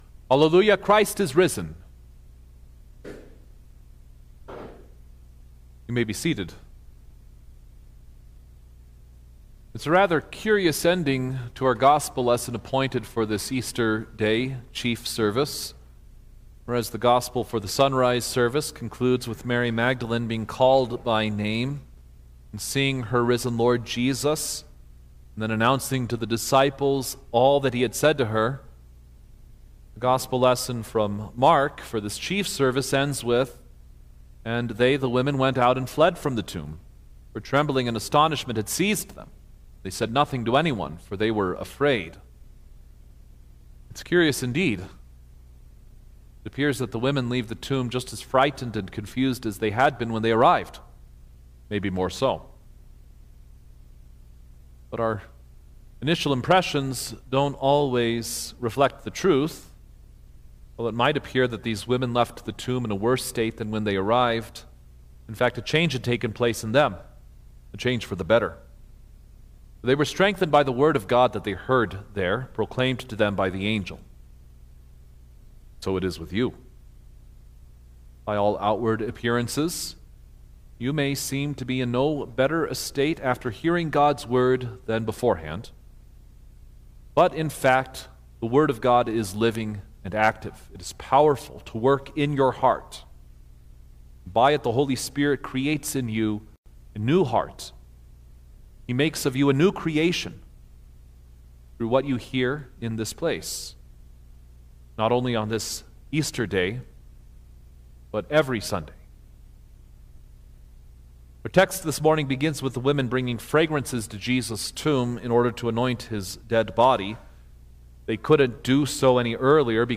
April-5_2026_Easter-Service_Sermon-Stereo.mp3